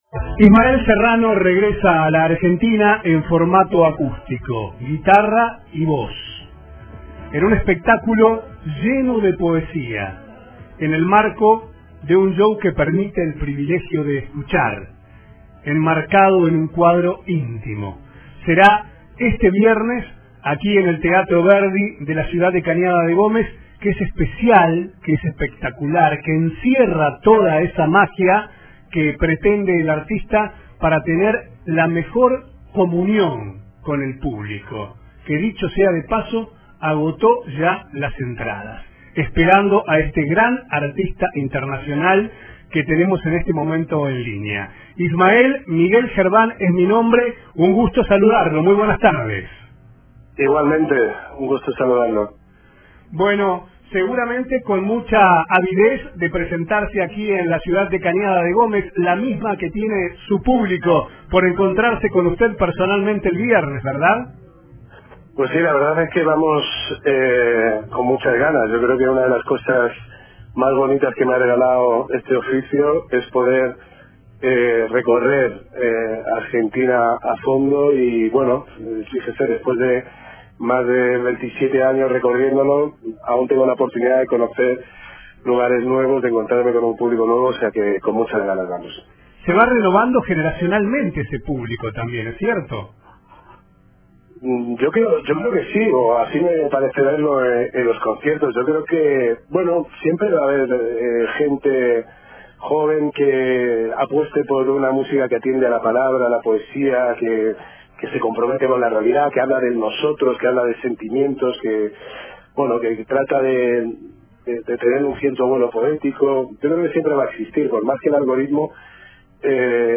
En una entrevista exclusiva en CNN Radio habló de su romance con el público argentino, de las redes sociales, la paternidad y su trayectoria.